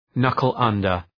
knuckle-under.mp3